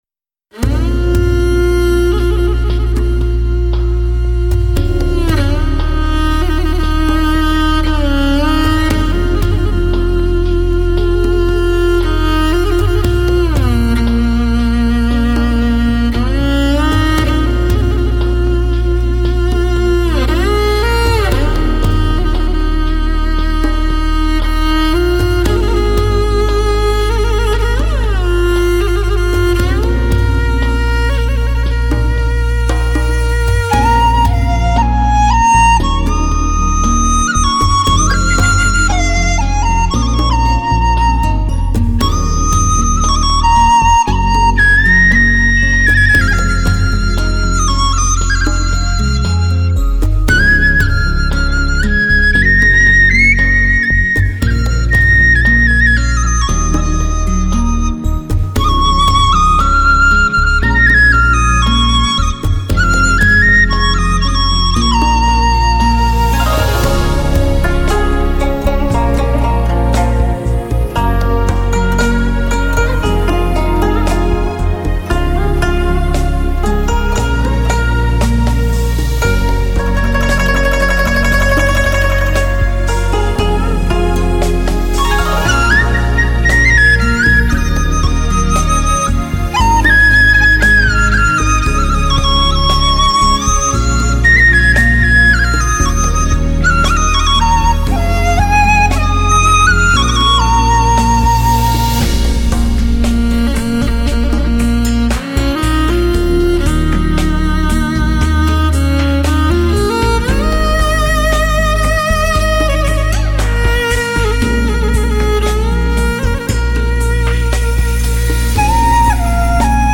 马头琴